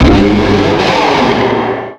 Cri de Méga-Blizzaroi dans Pokémon X et Y.
Cri_0460_Méga_XY.ogg